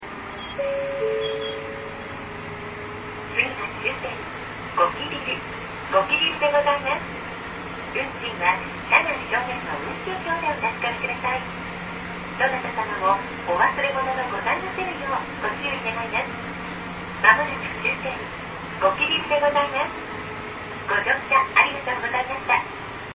・急行：MpegAudio(123KB)   ごきびる 北海道中央バス ＪＲ札沼線　新十津川駅